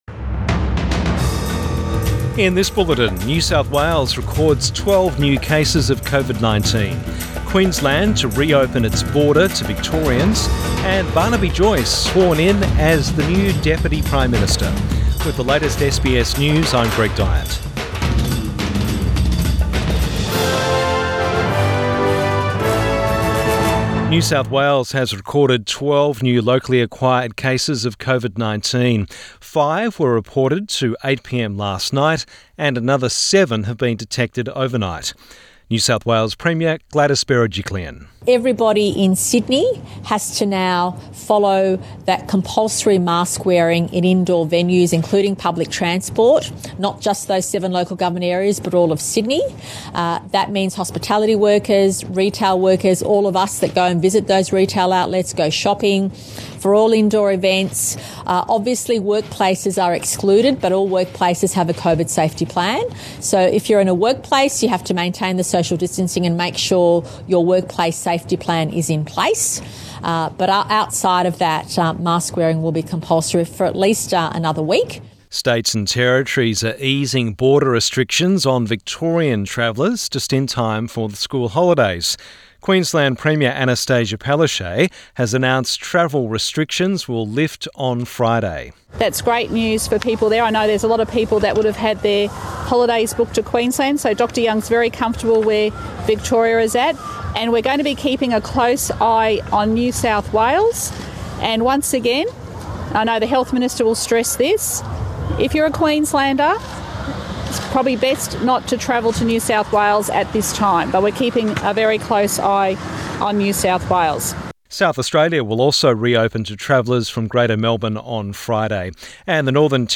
Midday bulletin 22 June 2021